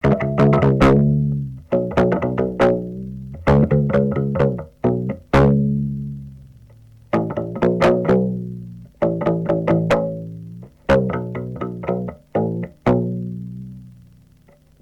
Tin-Can Bass